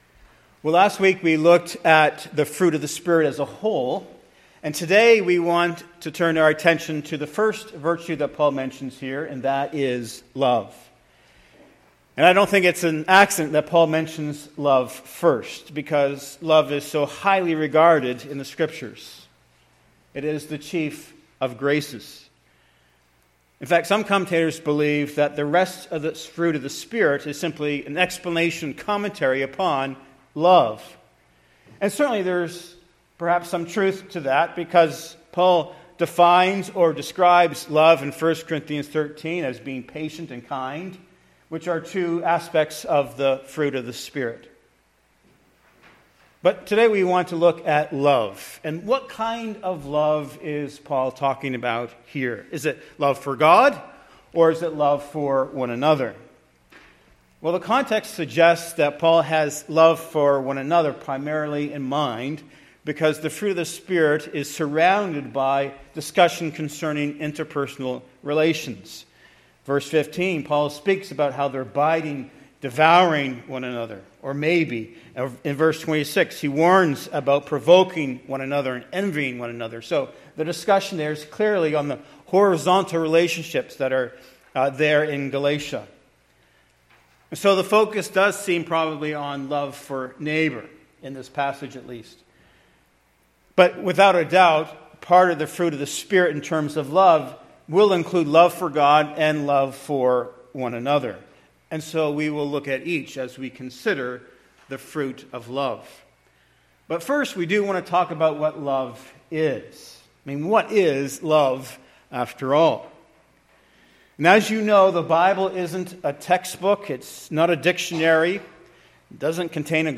This post contains a 24-minute Mp4 sermon of the fruit of the Holy Spirit, love.